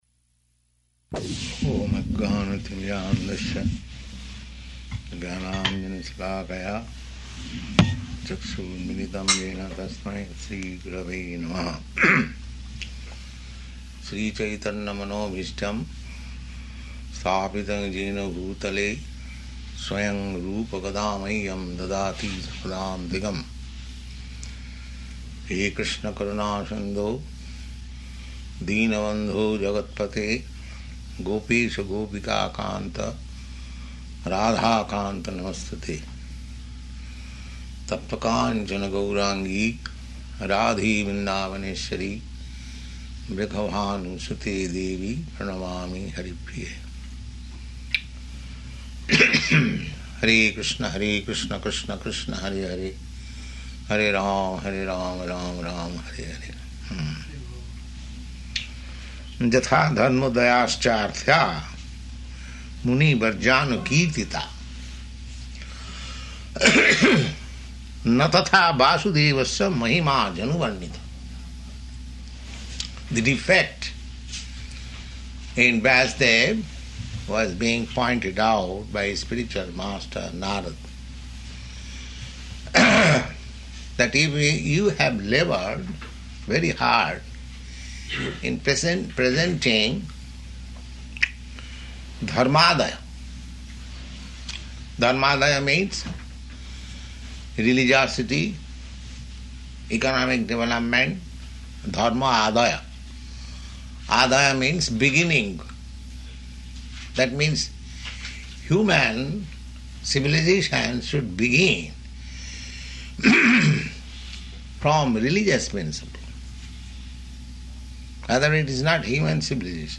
Location: New Vrindavan